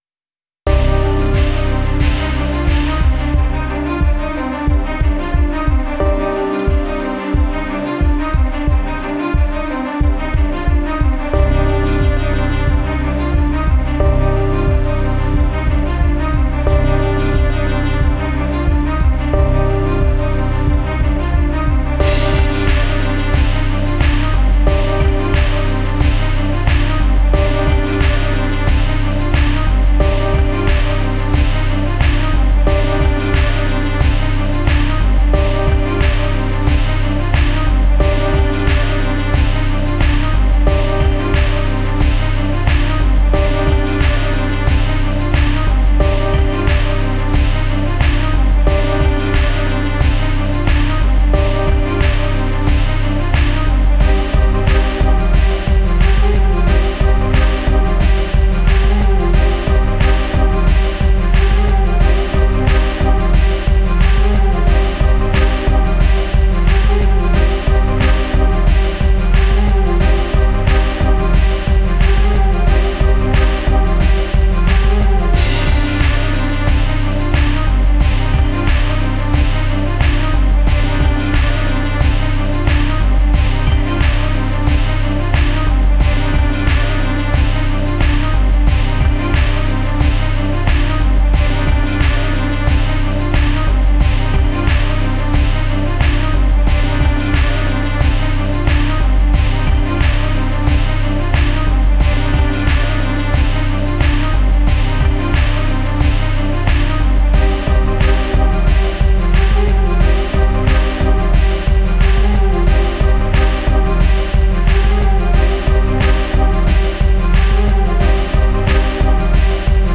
music is full of life and high energy.
are instrumental songs by design.